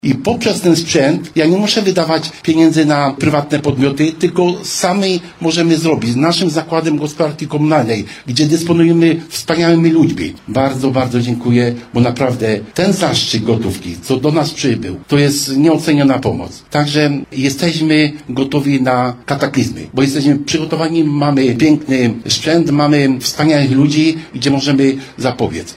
– Sprzęt pozwoli nam lepiej reagować na zagrożenia, a nawet kataklizmy – mówi wójt Krościenka nad Dunajcem Stanisław Tkaczyk.